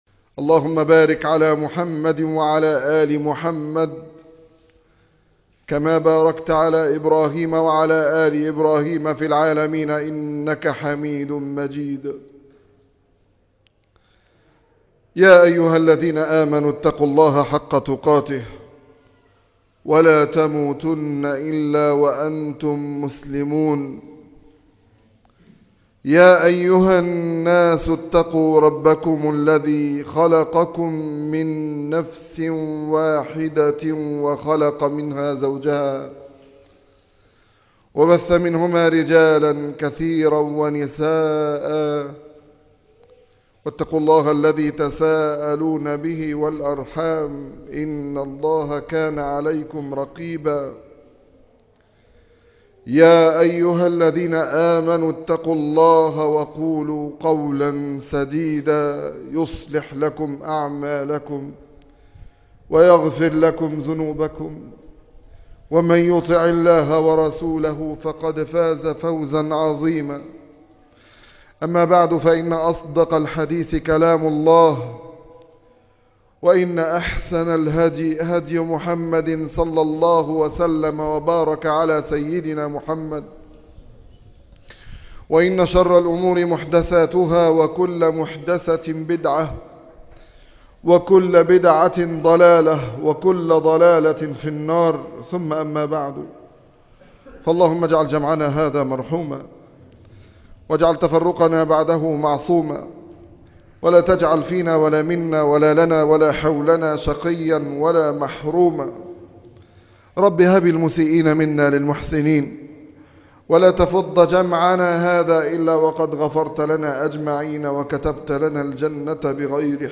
سر البركة - خطبة الجمعة